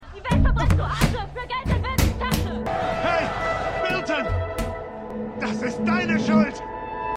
Extrapolations_1x01_Demonstrantin_Demonstrant3.mp3